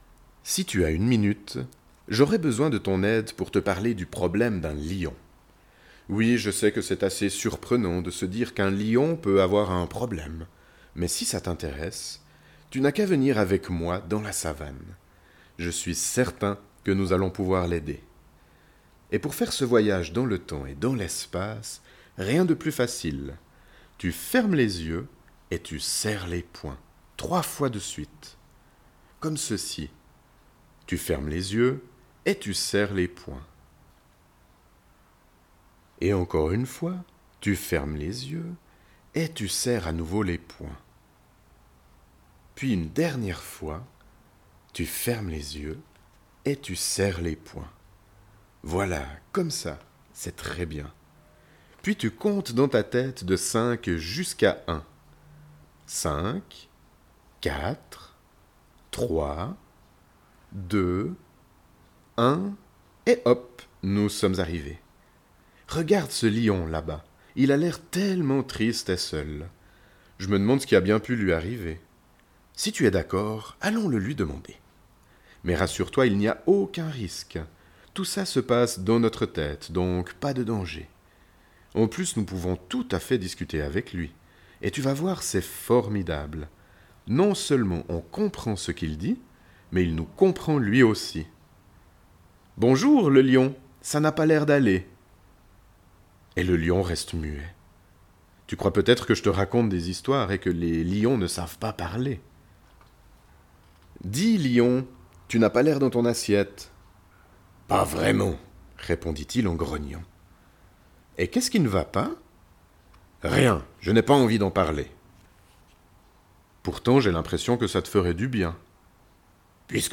Ces audios sont des contes métaphoriques à destination des enfants.